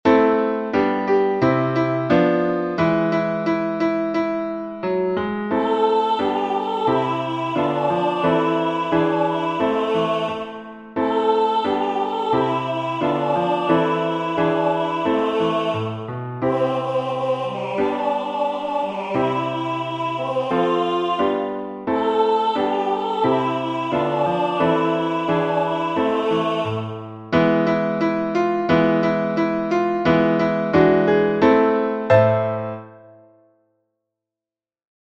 読み聞かせに使える挿入歌
カラオケ（0：35）